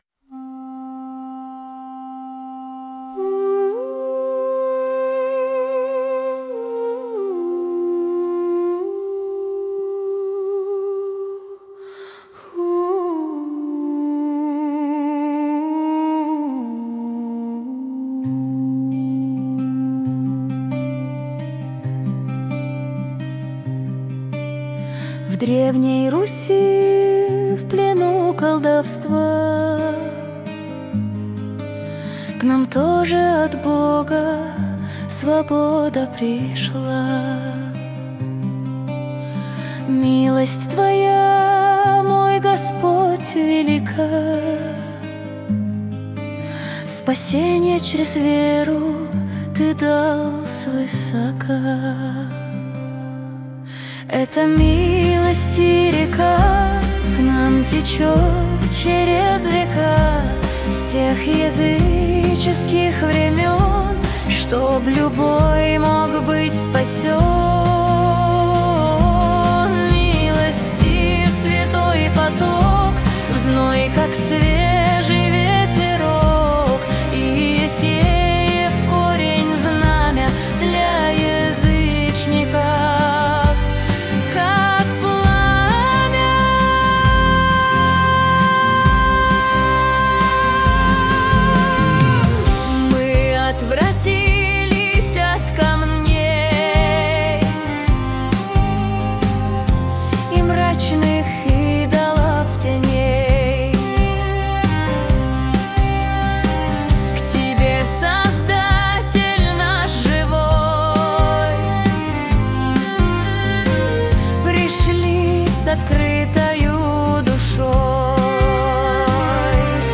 песня ai
244 просмотра 993 прослушивания 81 скачиваний BPM: 109